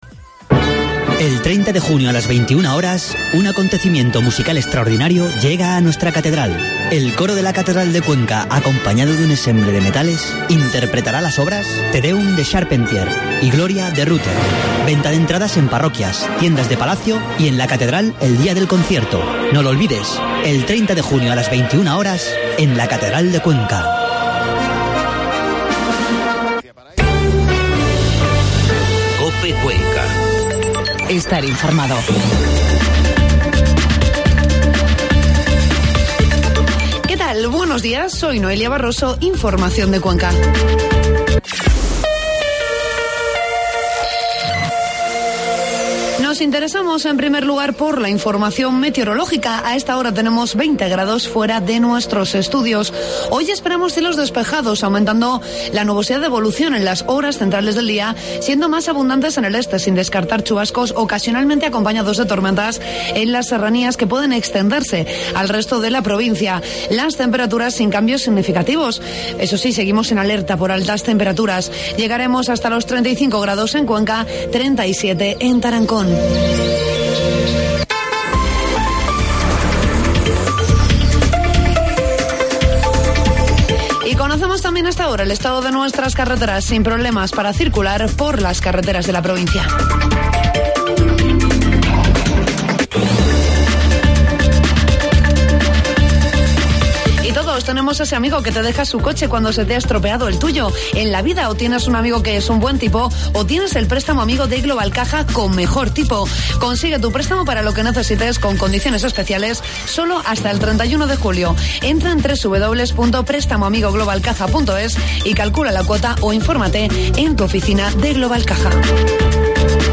AUDIO: Informativo matinal 26 de junio